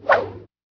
hop_spear1.wav